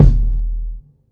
pstack_kick.wav